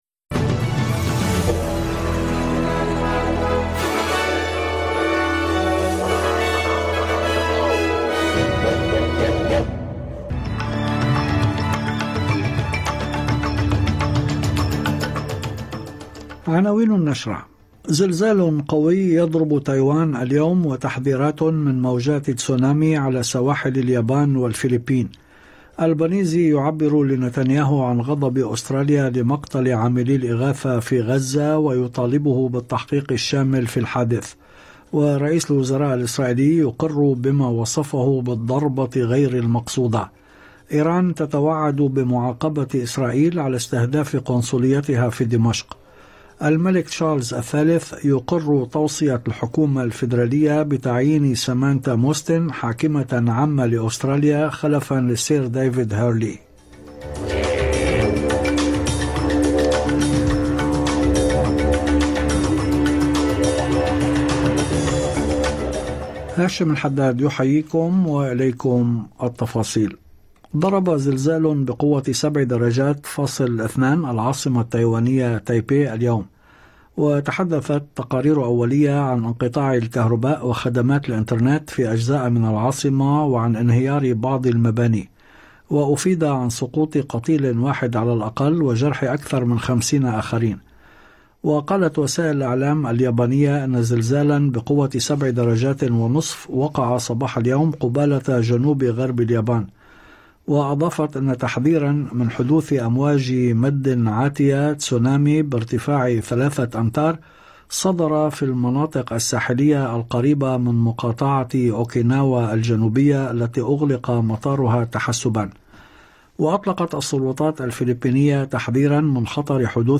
نشرة أخبار المساء 3/4/2024